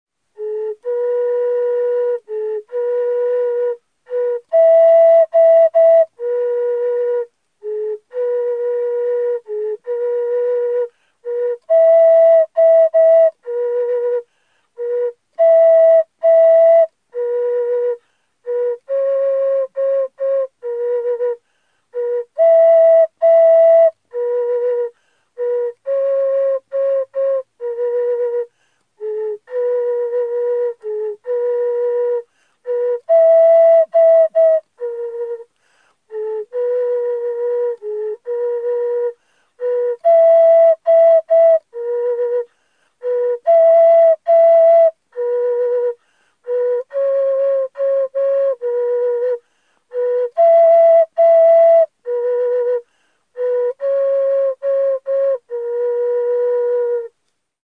Una canzone tradizionale brasiliana, “Petara”, in versione cantata e in versione suonata al flauto di bambù